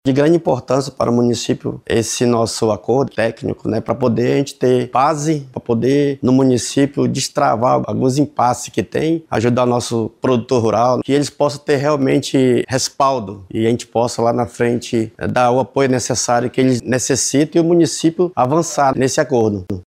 Para o prefeito de Manaquiri, Nelson Nilo, o acordo representa um avanço para o desenvolvimento da economia sustentável no município.